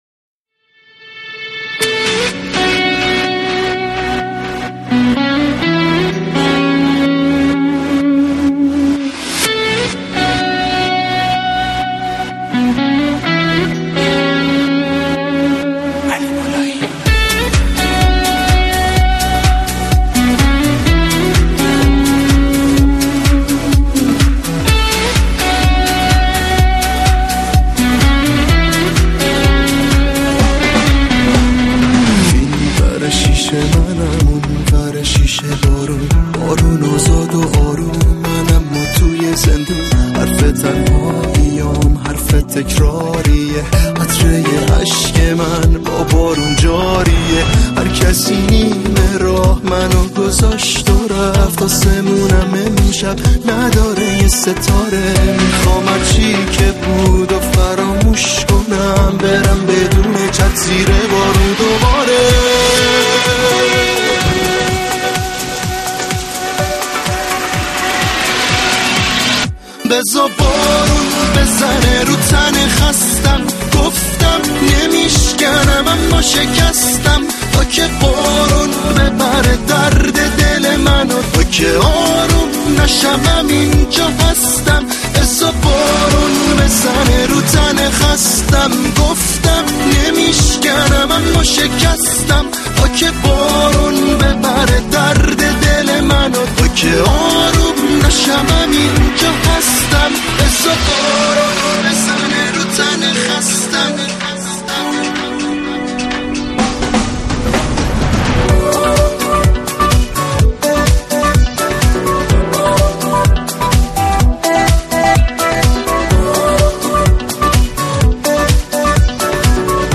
دانلود آهنگ غمگین